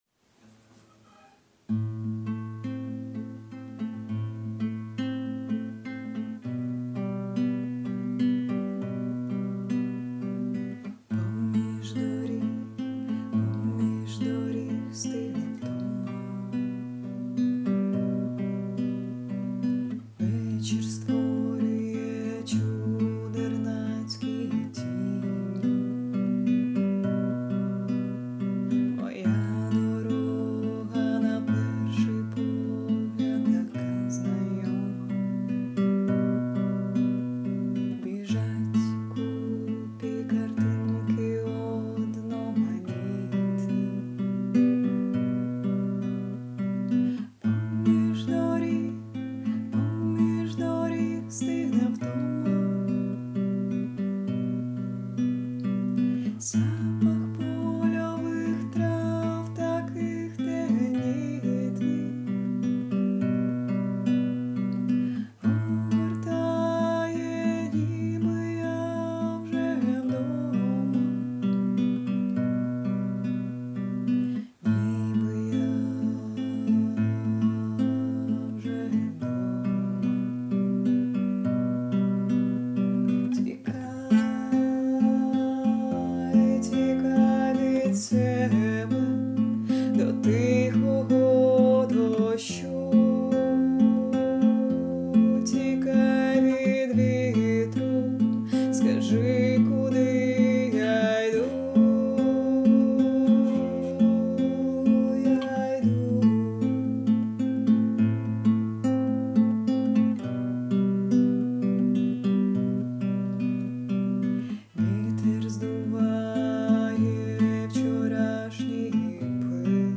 ТИП: Музика
СТИЛЬОВІ ЖАНРИ: Ліричний
ВИД ТВОРУ: Авторська пісня